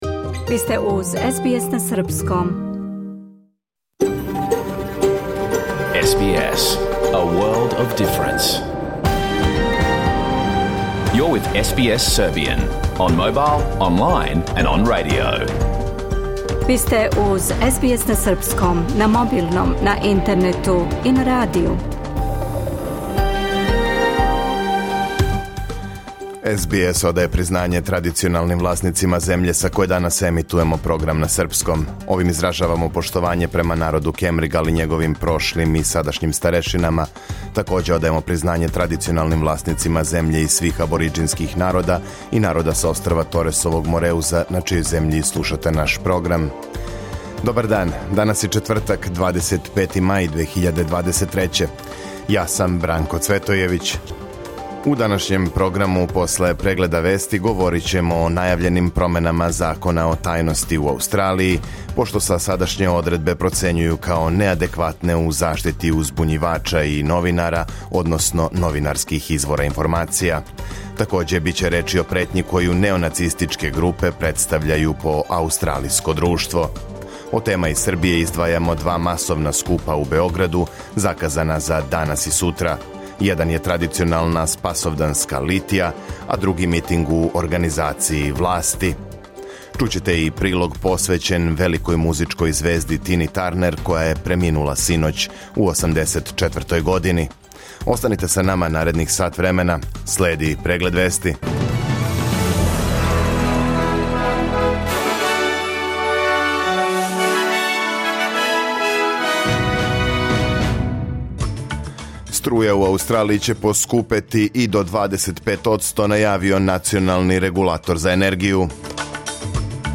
Програм емитован уживо 25. маја 2023. године
Ако сте пропустили данашњу емисију, можете да је слушате у целини као подкаст, без реклама.